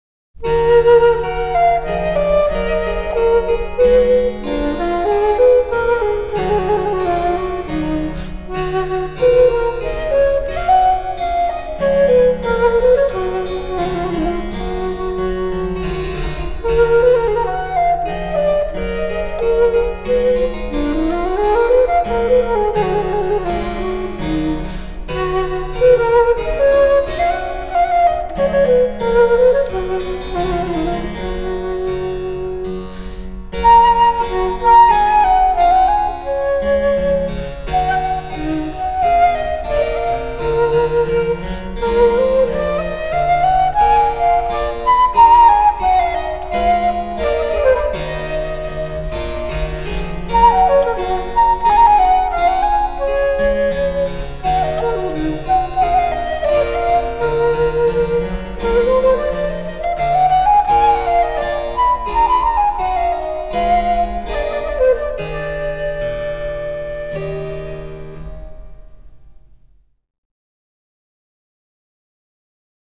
אז מצאתי ביצוע של חליל צד+צ'מבלו,
יש קצת יותר trill בביצוע הזה, אבל הוא בהחלט יפה:)
אבל זה ביצוע בארוקי לעילא ולעילא.
החליל מבצע פרשנות אחרת לסלסולים מהמורה לפסנתר.
הצ'מבלו מלווה נאמנה כיאות.